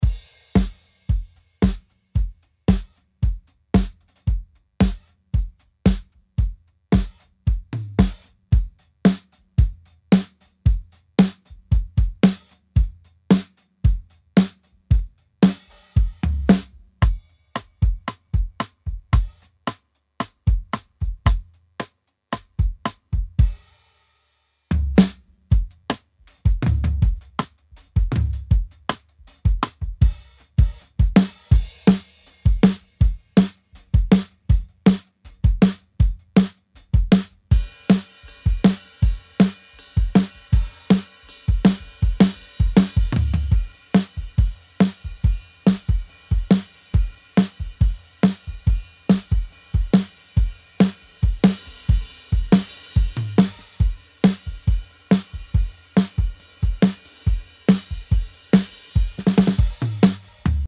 这些循环和采样旨在为任何流行音乐制作带来真实鼓手的自然感觉，同时保持明亮和有冲击力，使它们能够融入任何混音。